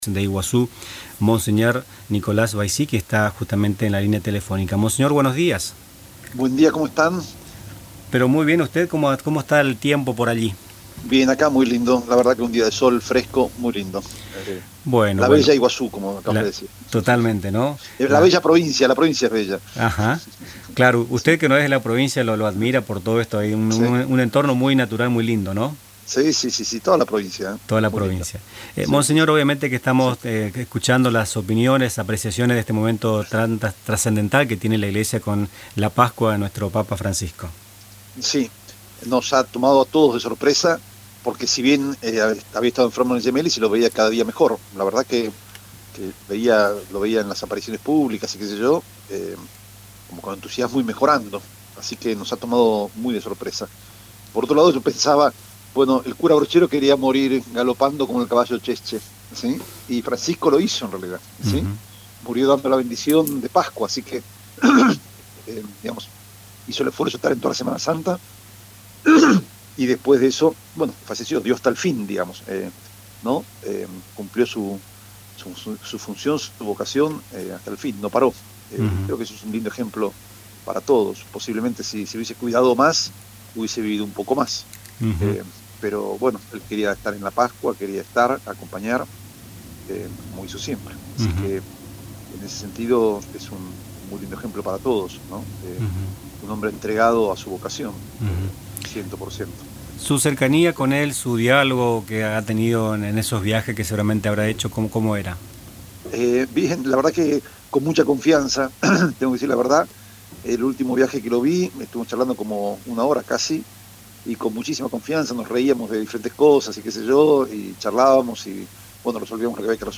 En «Nuestras Mañanas», entrevistamos a monseñor Nicolás Baisi, obispo de la diócesis de Iguazú, la segunda diócesis de Misiones. El prelado manifestó la sorpresa con que nos ha tocado esta noticia, porque se lo veía mejorando, pero nos deja el ejemplo de haberlo entregado todo, hasta el final.